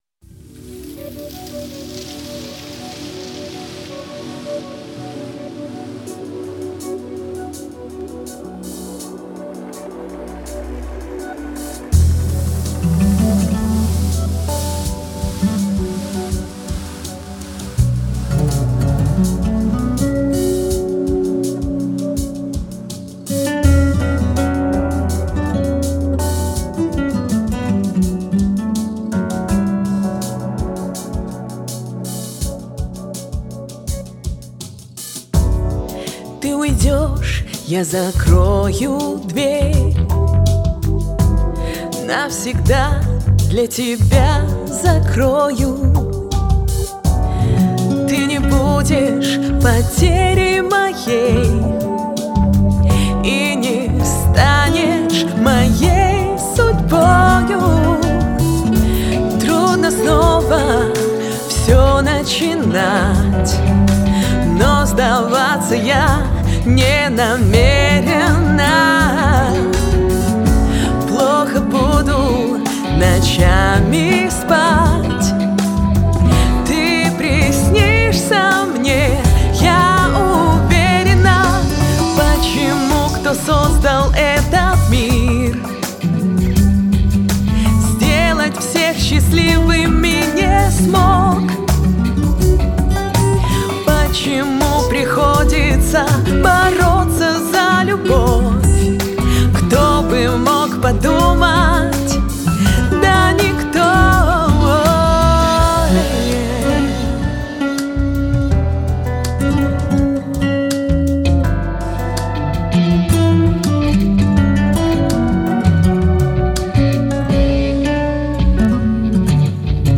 Для меня по сведению вроде как нормально.Но,скорее всего это не так.Буду признателен за критику и тем более за советы в плане улучшения. Сразу извиняюсь за гитары.Конечно,хотелось бы,чтобы партии были сыграны гитаристом,да на гитаре.но,тут уж что есть-то есть.